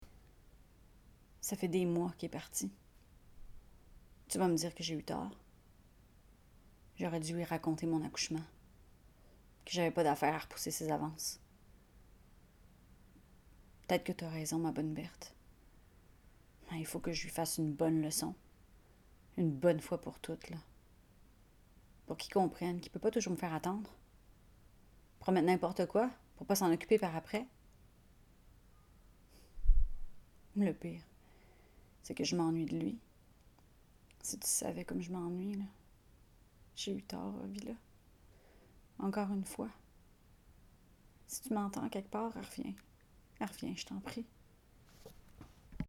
12 - 94 ans - Mezzo-soprano